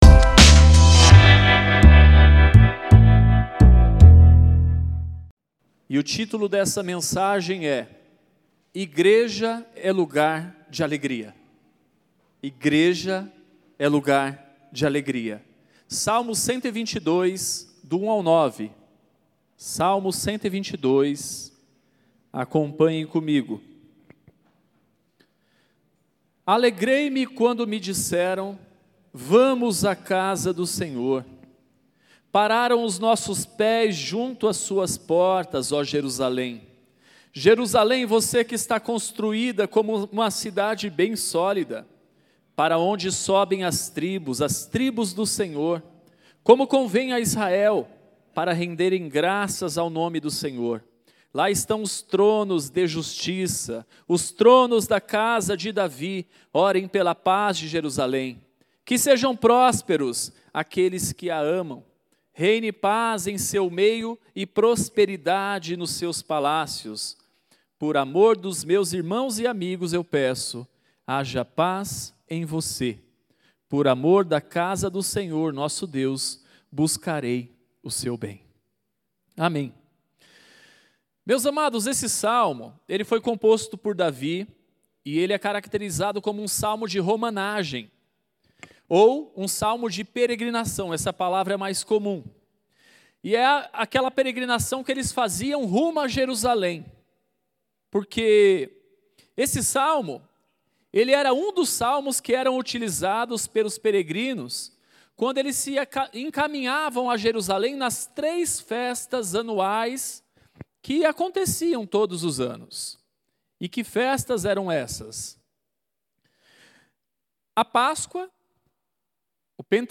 Culto especial para celebrarmos os 66 anos da nossa comunidade de fé!